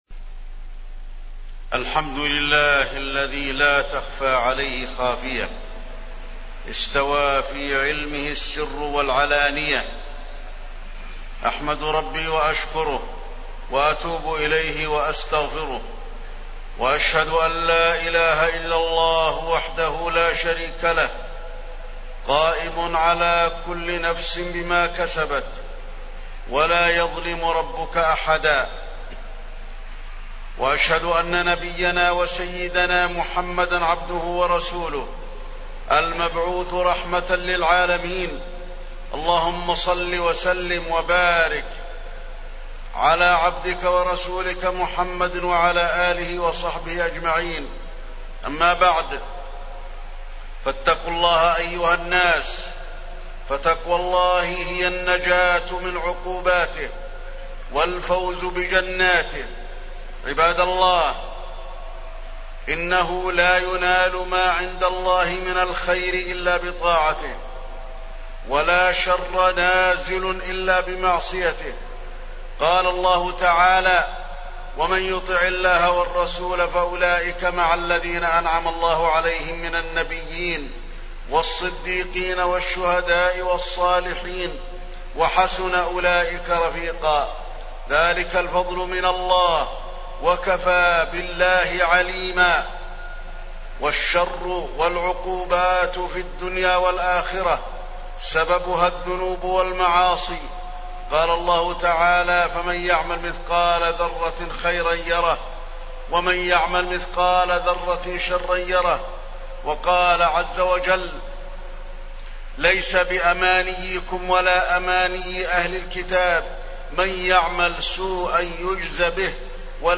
تاريخ النشر ١٤ شعبان ١٤٢٩ هـ المكان: المسجد النبوي الشيخ: فضيلة الشيخ د. علي بن عبدالرحمن الحذيفي فضيلة الشيخ د. علي بن عبدالرحمن الحذيفي العقوبات نتيجة المعاصي